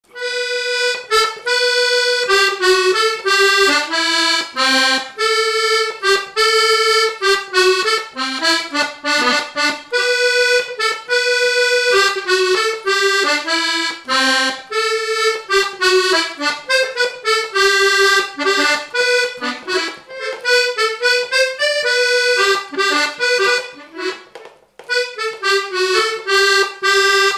Note marche Localisation Rochetrejoux
Résumé instrumental